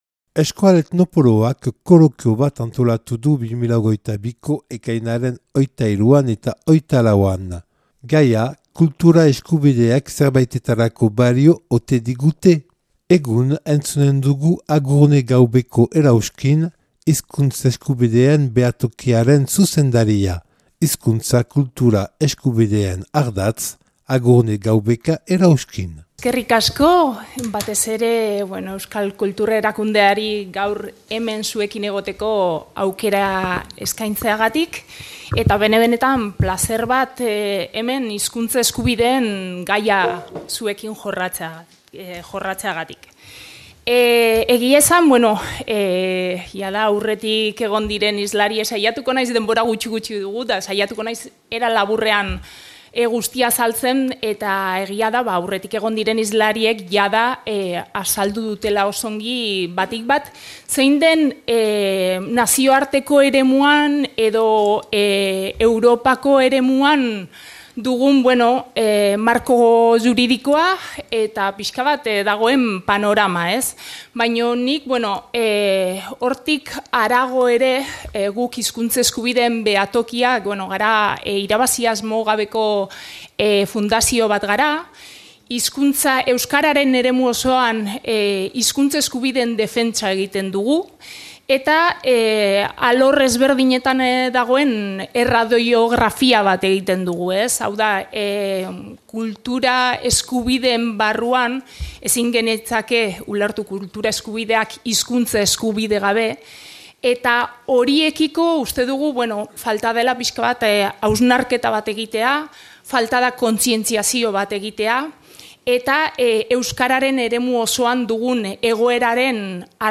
[Euskal Etnopoloaren kolokia Baionako Euskal Museoan 2022. Ekainaren 23-24]